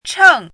chinese-voice - 汉字语音库
cheng4.mp3